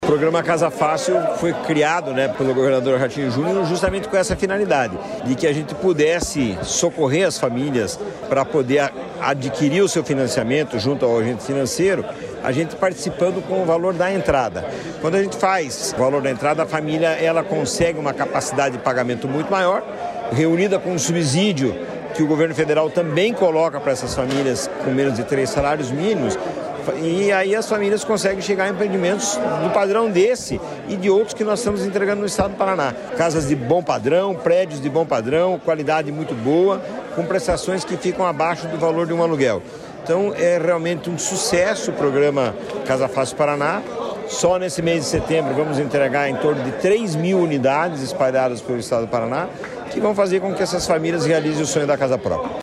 Sonora do presidente da Cohapar, Jorge Lange, sobre a entrega de moradias para 85 famílias de Cascavel